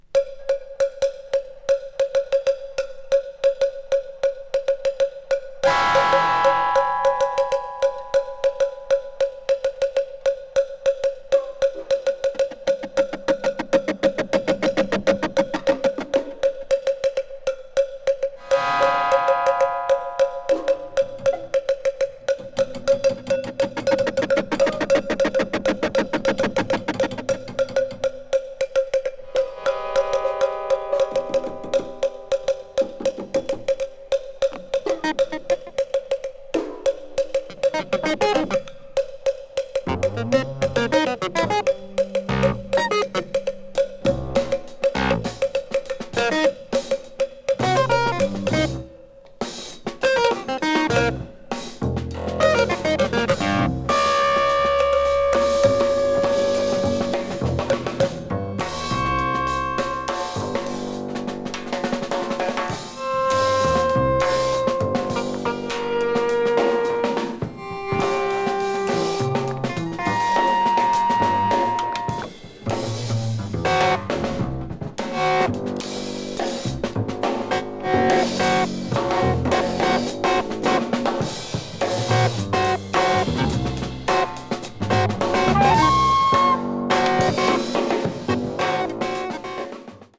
piano
bass
drums
avant-jazz   contemporary jazz   ethnic jazz   free jazz